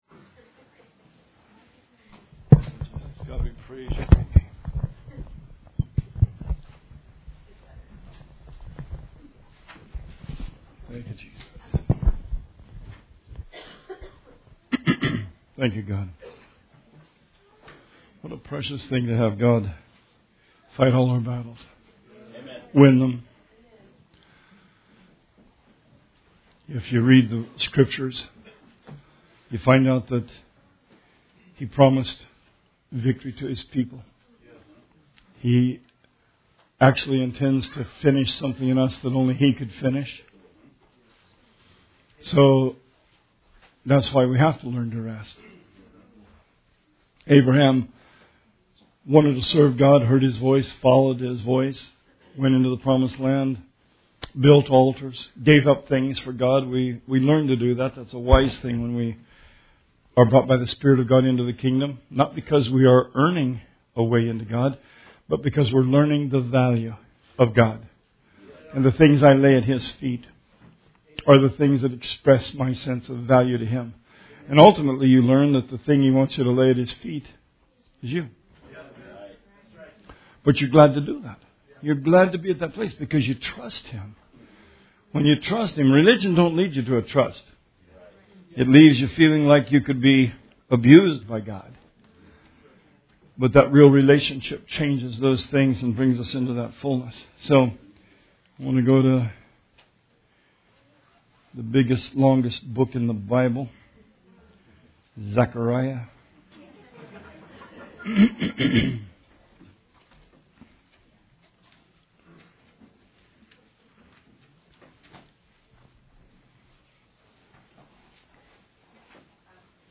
Sermon 2/11/18